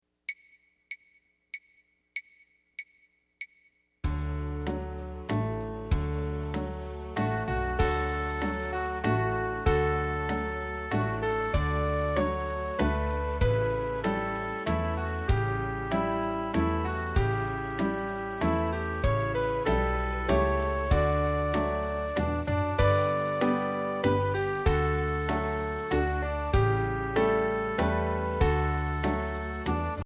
Voicing: Piano/CD